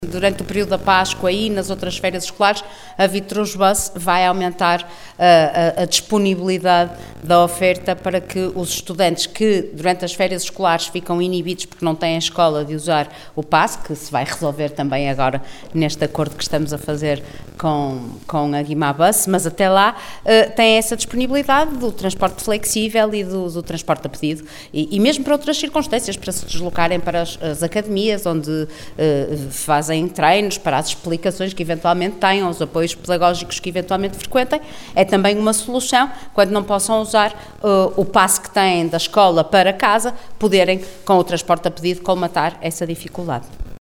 Declarações da vereadora da Câmara Municipal de Guimarães, Vânia Dias da Silva.